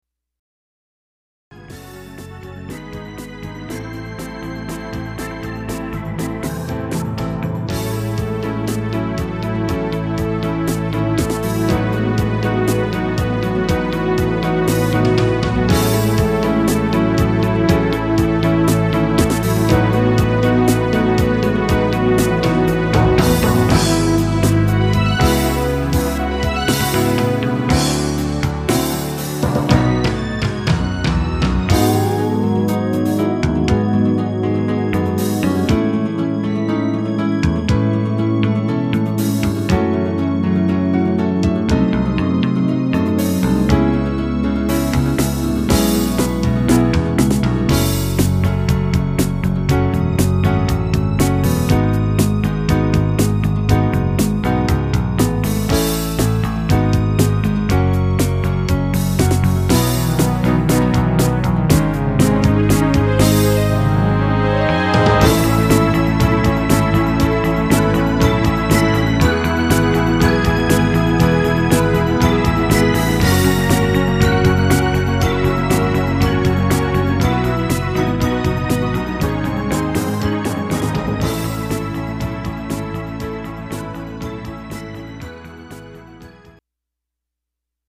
試聴曲の音源